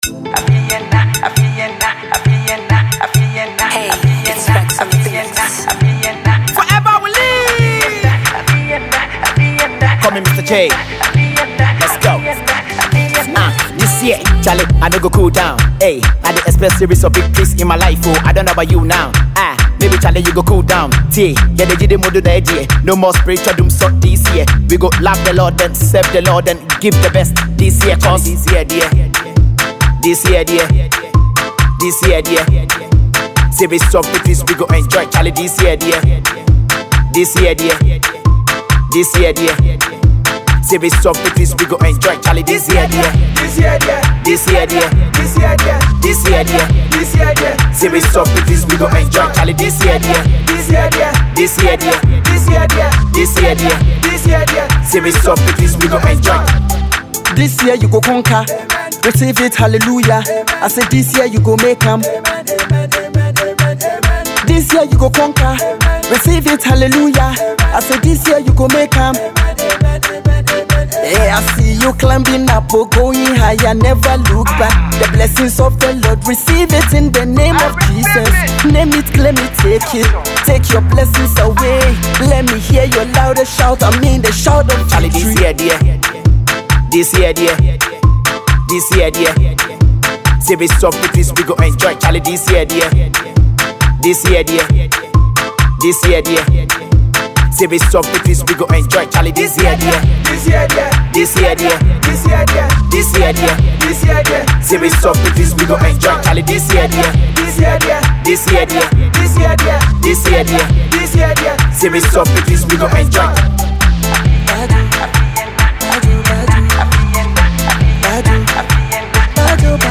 Get your faith confessions on and dance to the groove.